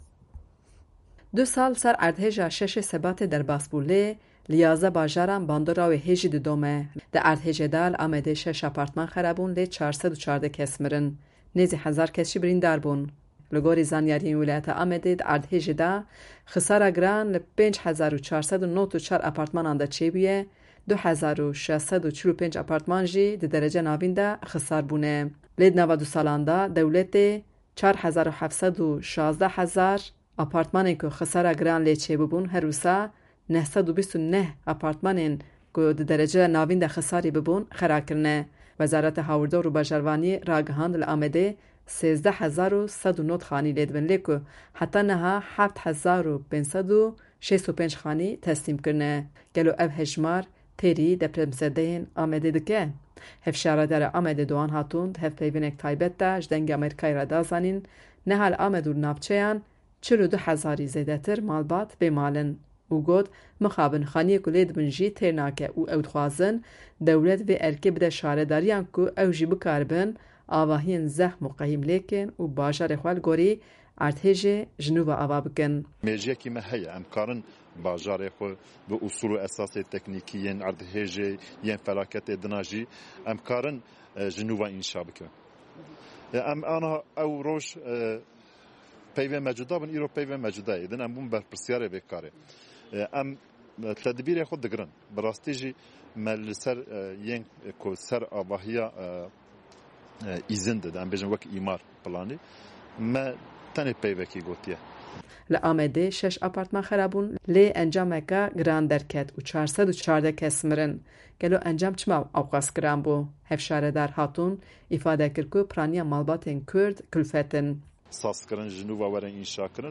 Hevşaredarê Amedê Dogan Hatûn di hevpeyvîneka taybet ya Dengê Amerîka de da zanîn ku niha li Amed û navçeyan wê ji 42 hezarî zêdetir malbat bê mal in.